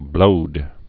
(blōd)